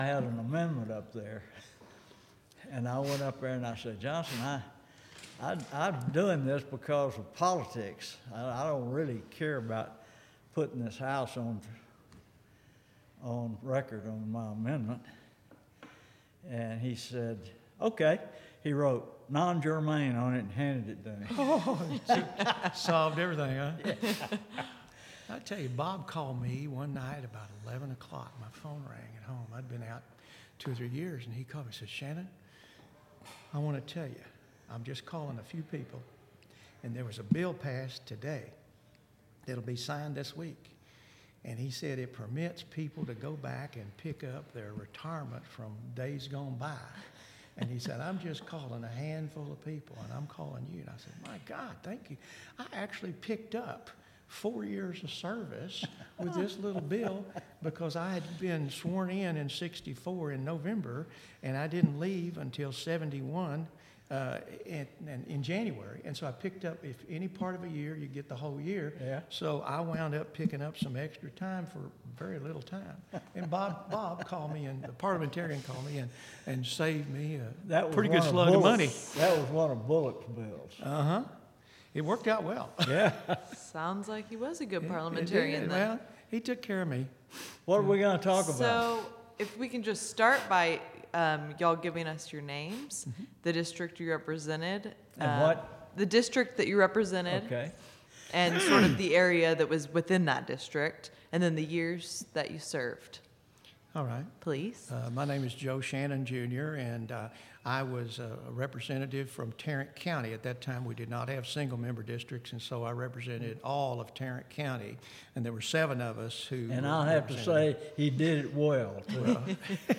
Oral history interview with Joe Shannon, Jr. and Don Adams, 2017.